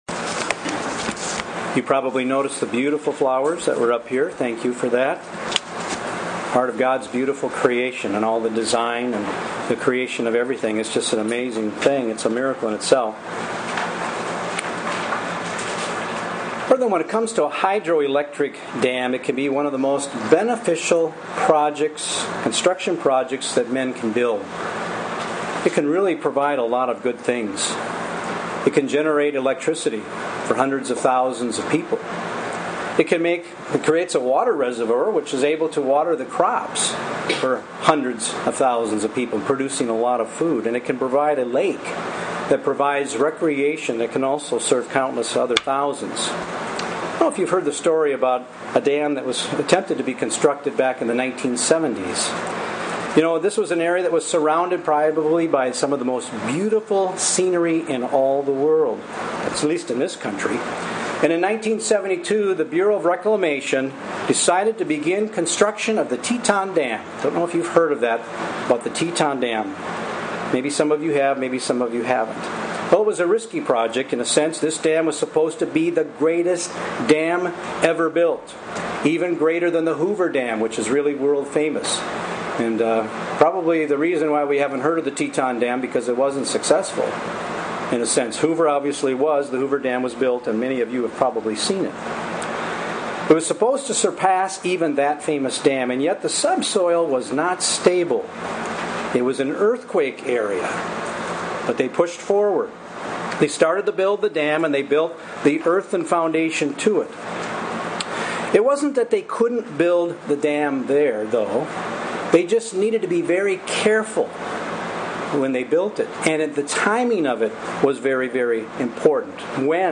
This sermon addresses the responsibility a Christian has to be a peacemaker.